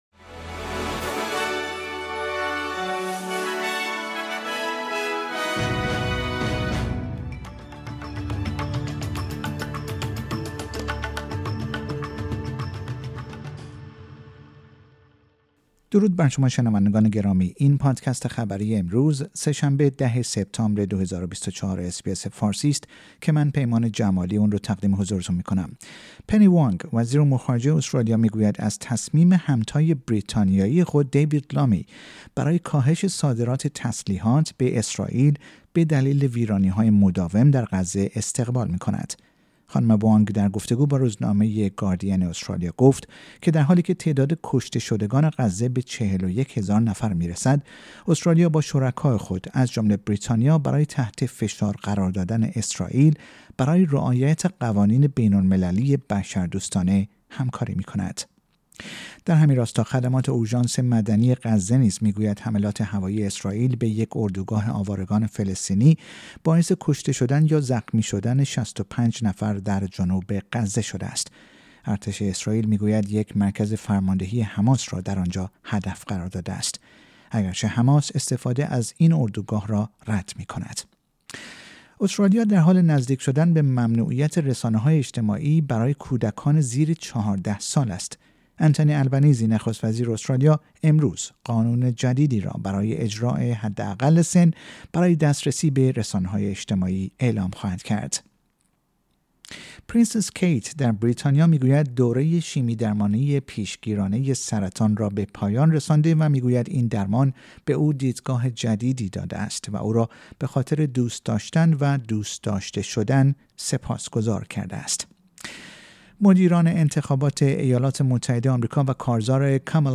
در این پادکست خبری مهمترین اخبار استرالیا در روز سه شنبه ۱۰ سپتامبر ۲۰۲۴ ارائه شده است.